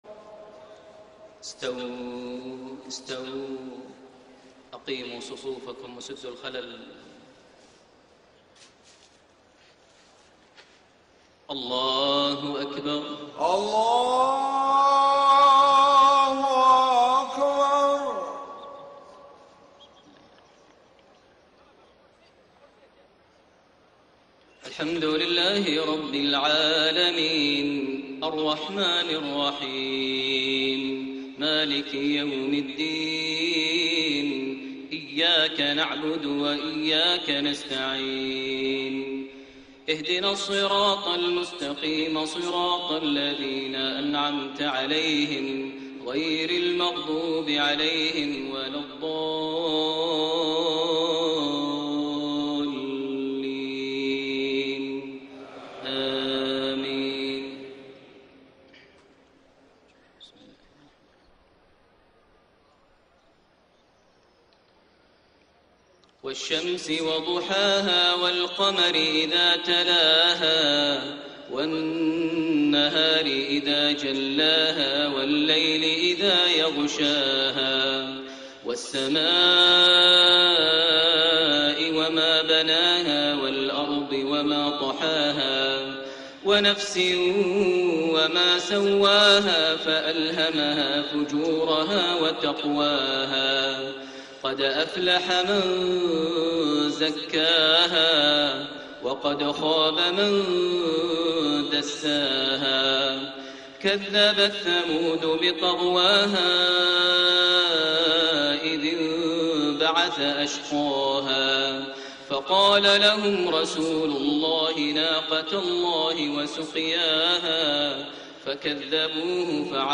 Maghrib prayer from Surah Ash-Shams and Al-Lail > 1433 H > Prayers - Maher Almuaiqly Recitations